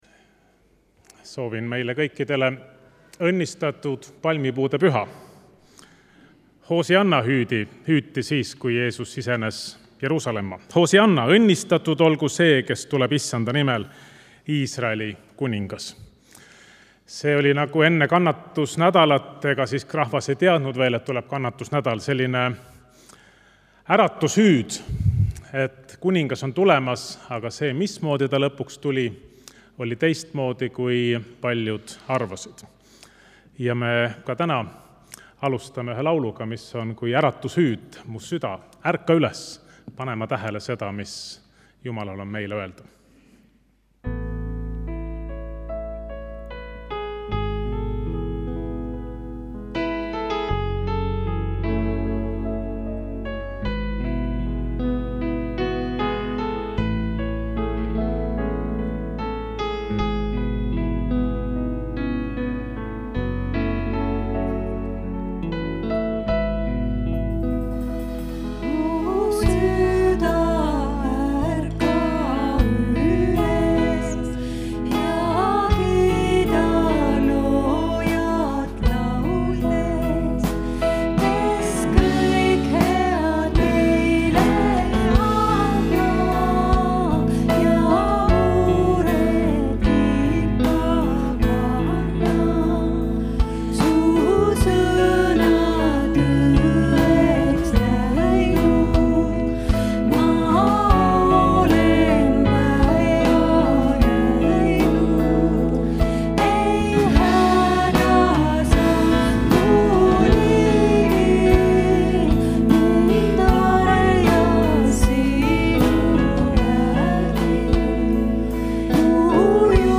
Jutlus
Muusika: Kolgata koguduse ülistusbänd